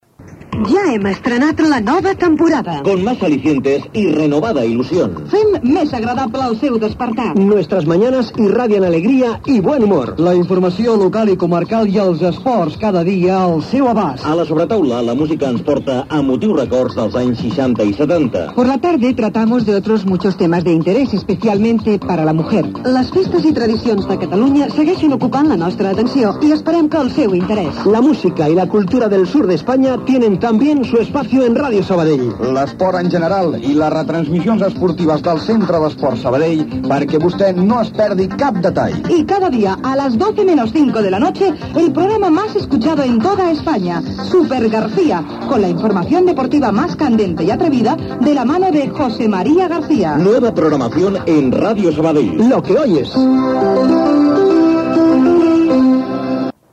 Promoció de la programació 1992/1993